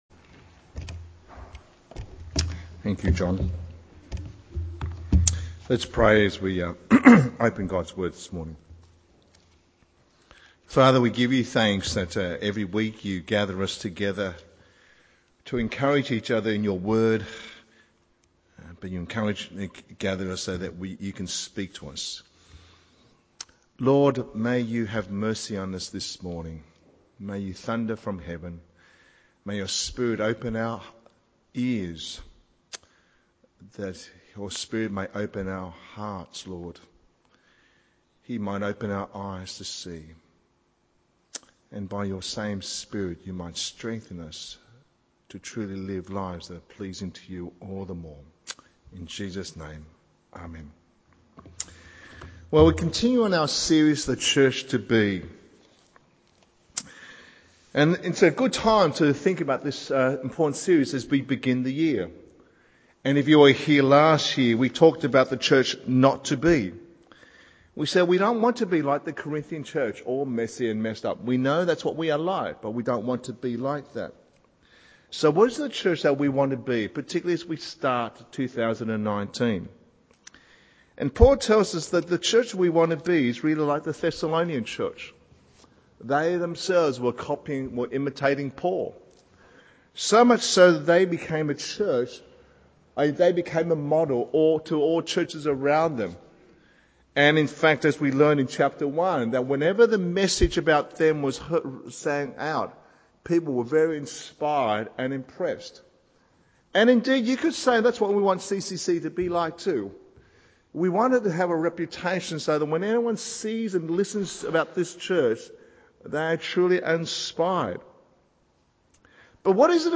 Sermons English - The Chinese Christian Church